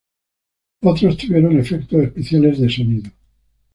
so‧ni‧do
/soˈnido/